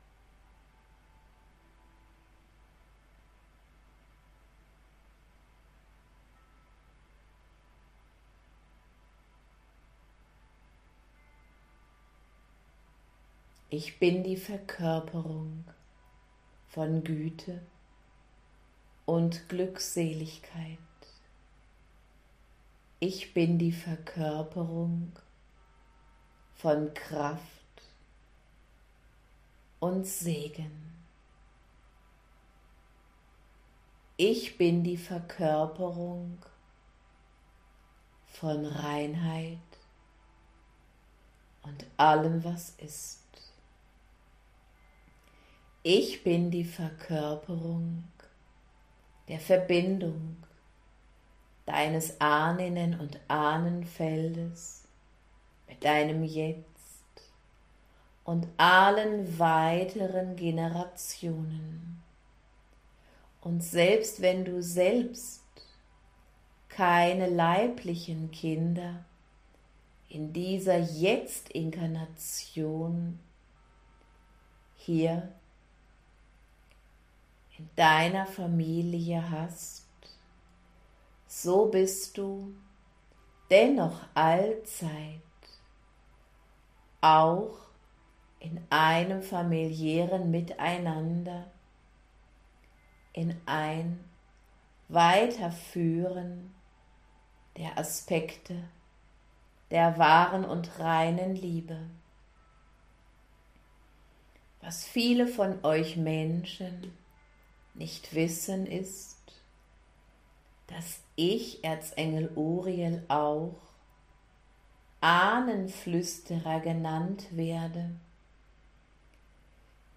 Höchste Energieübertragung, Channeling und Impulsabend
♥GRATIS Auszug (ca. 7 Minuten): Erzengel Uriel voller Glückseligkeit für dich.